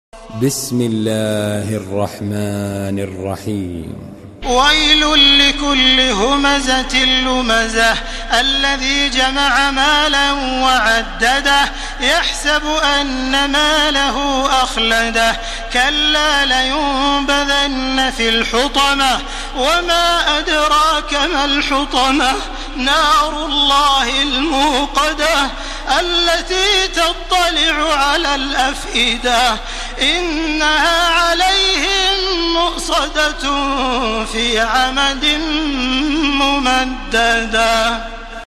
تحميل سورة الهمزة بصوت تراويح الحرم المكي 1431